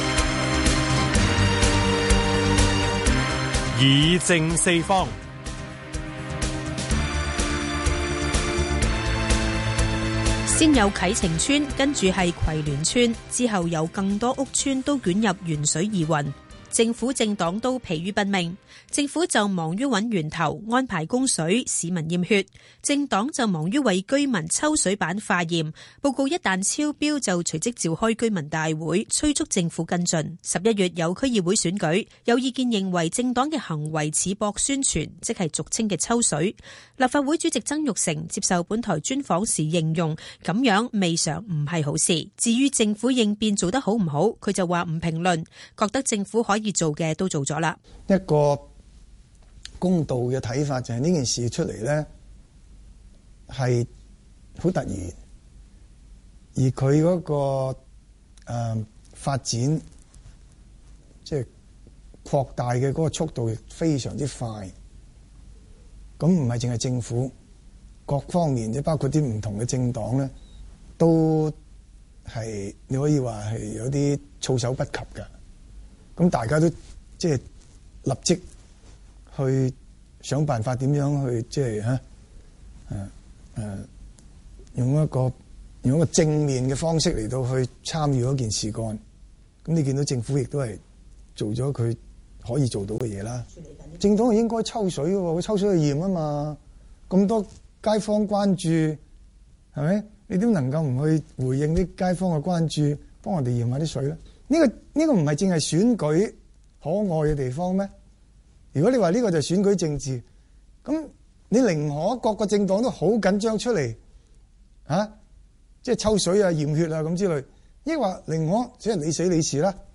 香港電台《議政四方》訪問